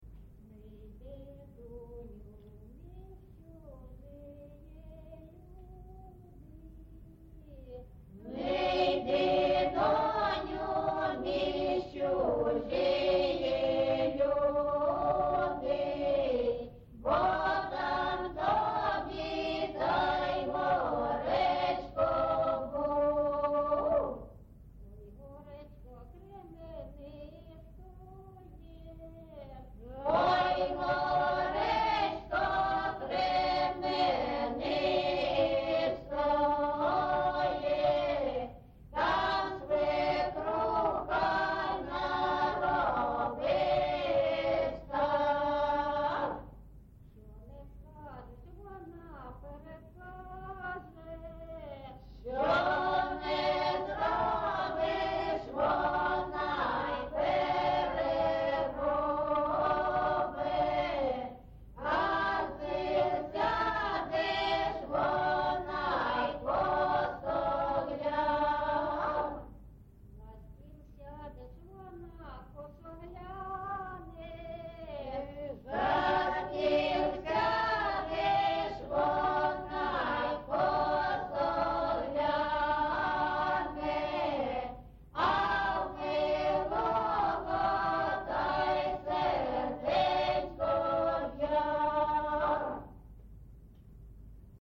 ЖанрВесільні
Місце записус. Маринівка, Шахтарський (Горлівський) район, Донецька обл., Україна, Слобожанщина